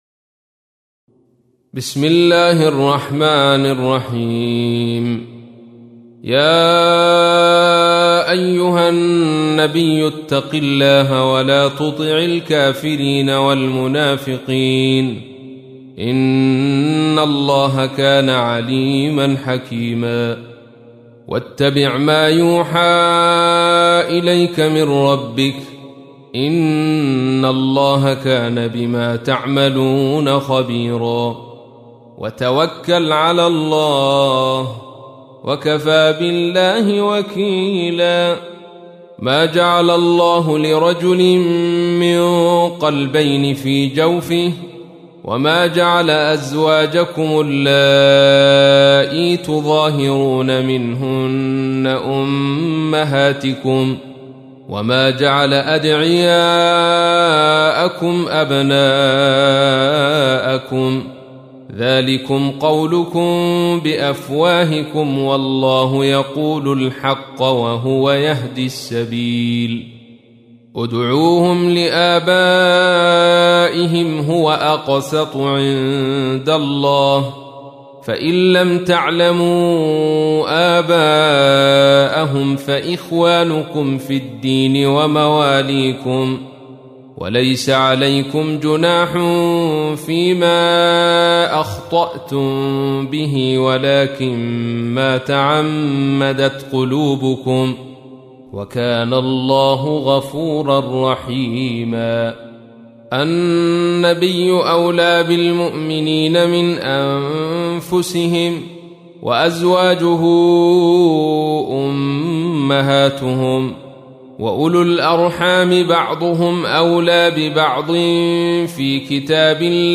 تحميل : 33. سورة الأحزاب / القارئ عبد الرشيد صوفي / القرآن الكريم / موقع يا حسين